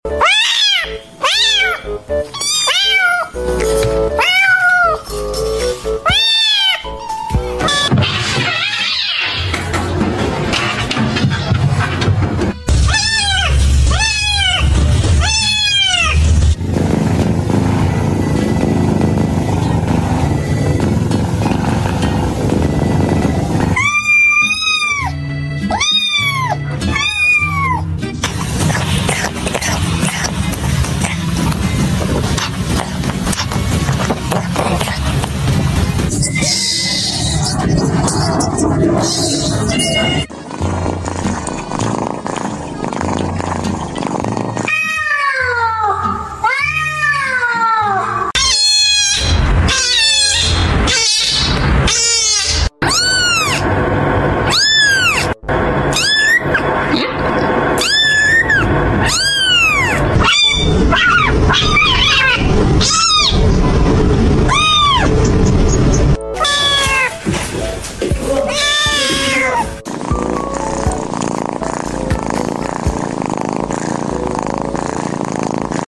Kittens Funny Moment and Laughing sound effects free download